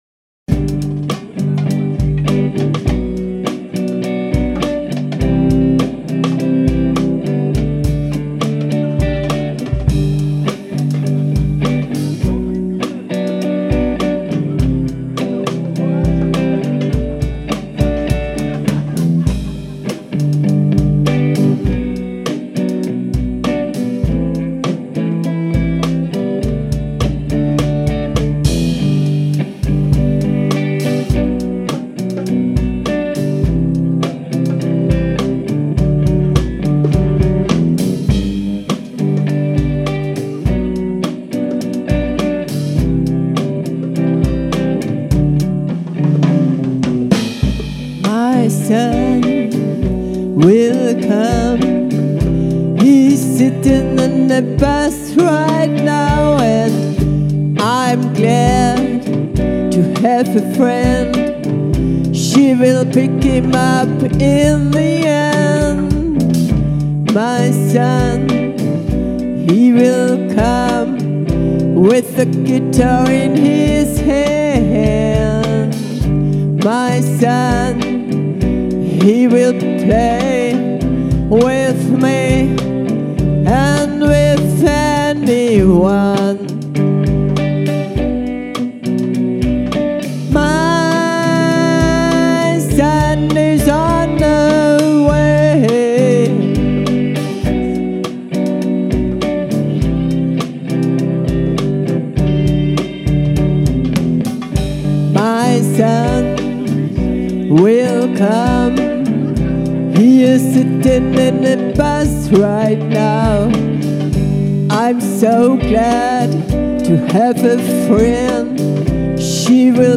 guitar
lead guitar
vocals & lyrics
bass
drums
perc
recorded live Aug. 2022 at Cafe Sack